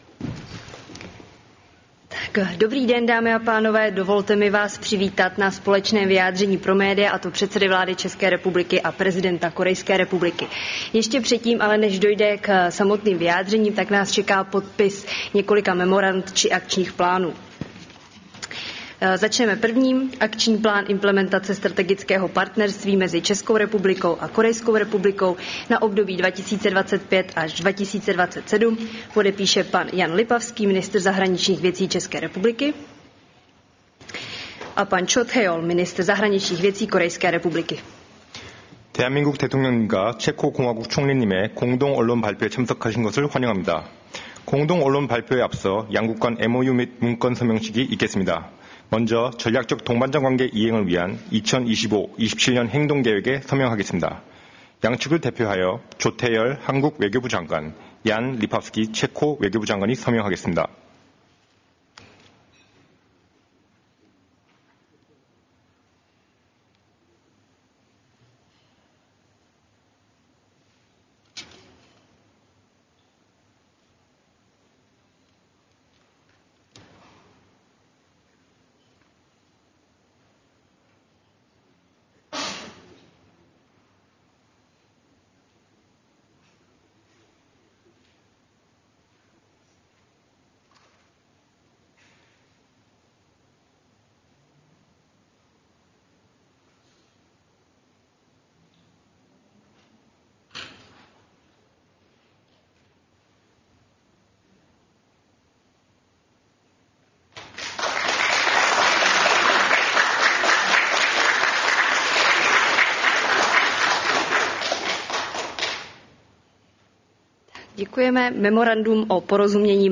Tiskový brífink k návštěvě prezidenta Korejské republiky Jun Sok-jola, 20. září 2024